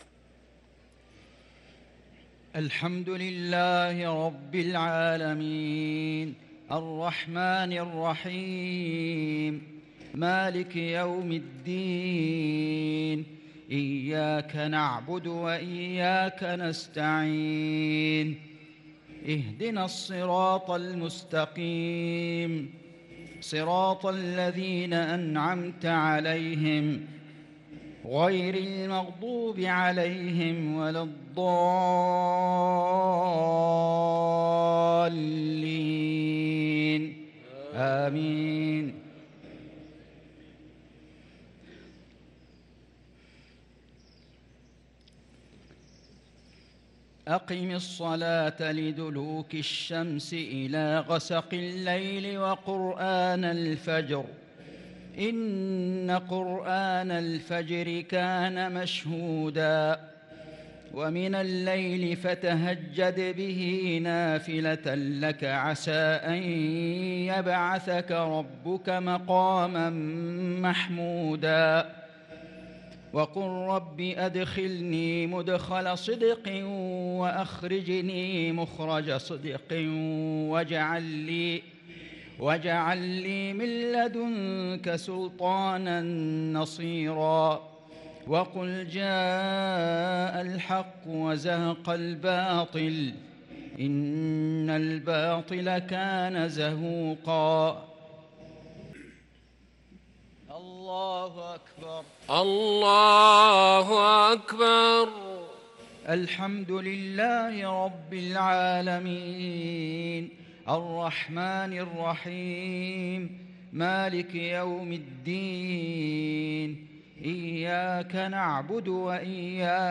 صلاة المغرب للقارئ فيصل غزاوي 14 رمضان 1443 هـ
تِلَاوَات الْحَرَمَيْن .